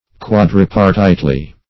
Quadripartitely \Quad*rip"ar*tite*ly\, adv.
quadripartitely.mp3